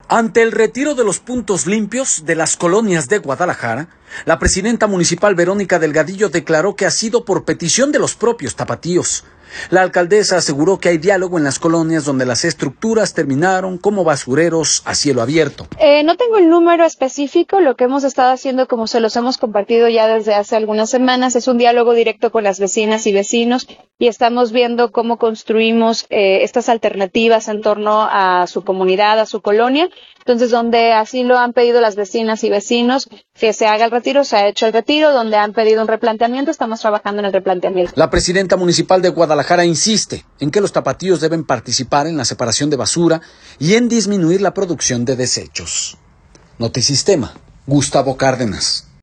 Ante el retiro de los “Puntos Limpios” de las colonias de Guadalajara, la presidenta municipal, Verónica Delgadillo, declaró que ha sido por petición de los propios tapatíos.